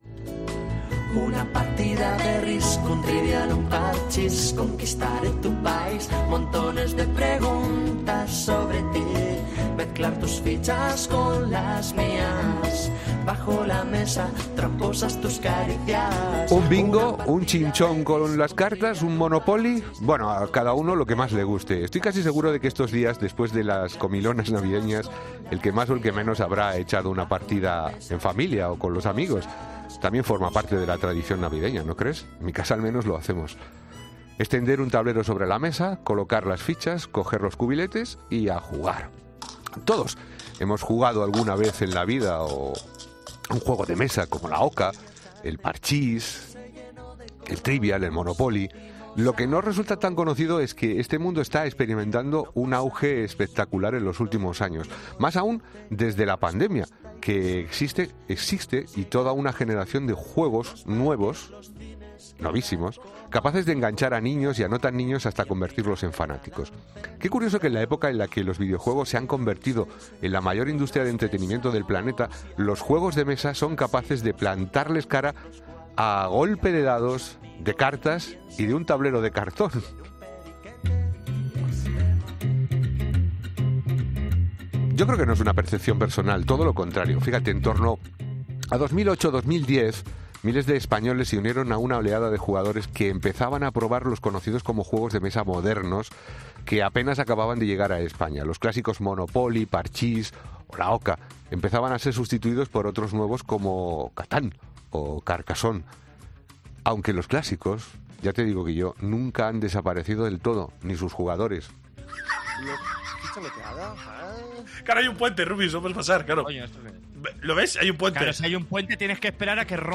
Hablamos en 'Herrera en COPE' con dos de los creadores de uno de los juegos de mesas modernos número 1 en ventas online en España